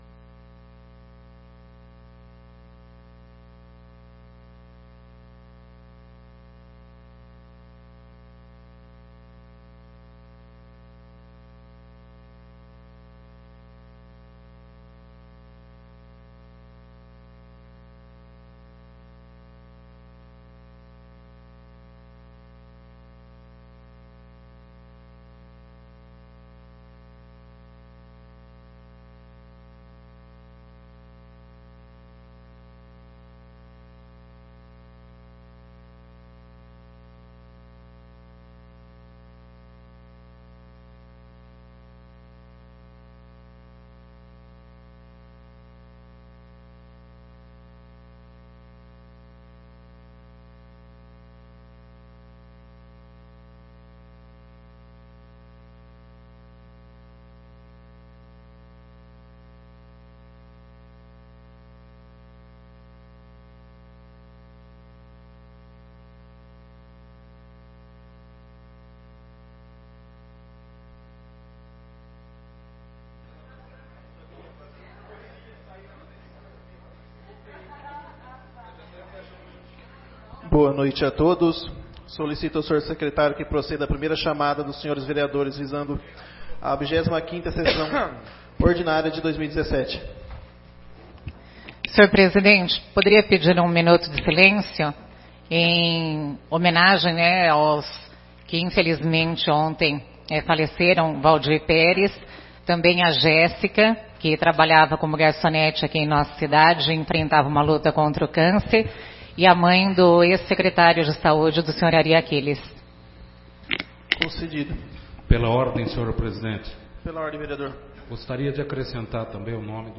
25ª Sessão Ordinária de 2017